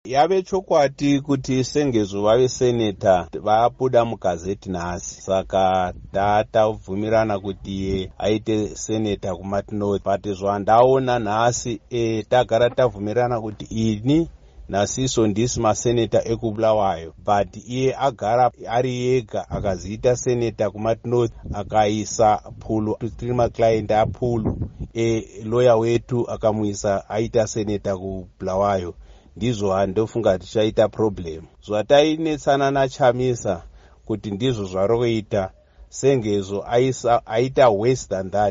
Mashoko a Va Albert Mhlanga.